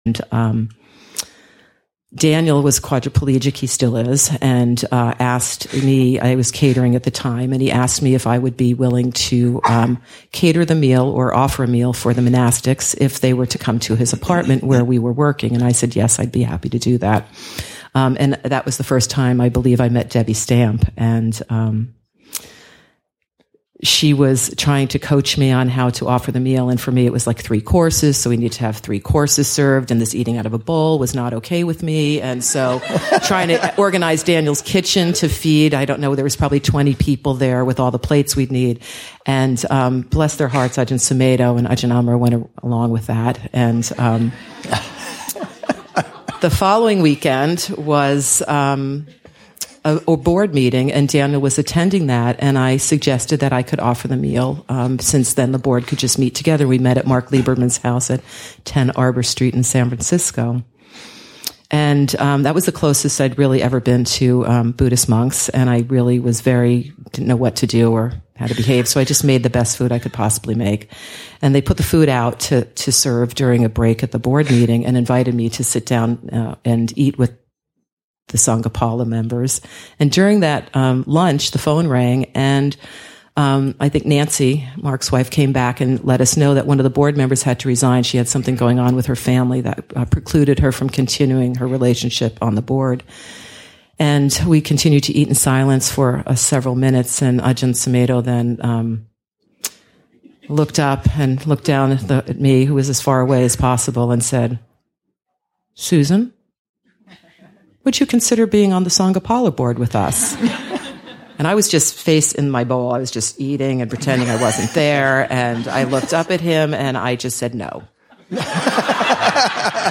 1. Recollection: How I came to Buddhism and the beginnings of the Saṅghapāla Foundation.